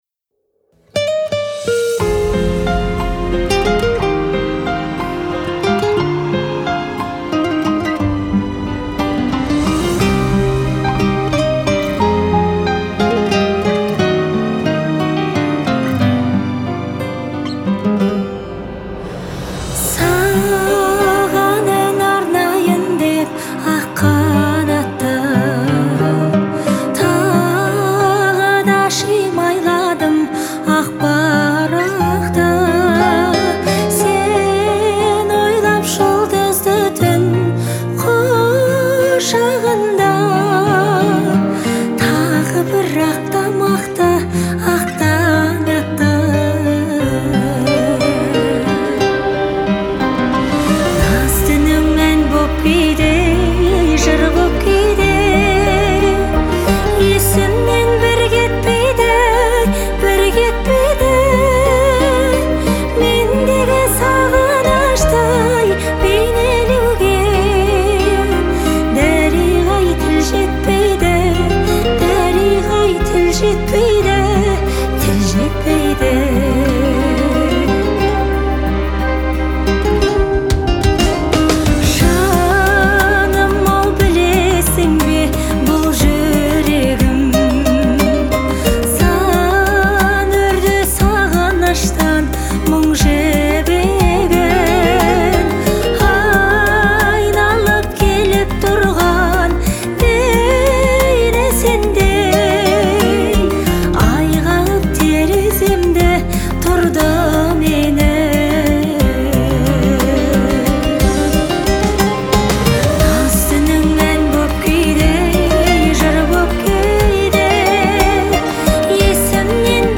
это трек в жанре казахской поп-музыки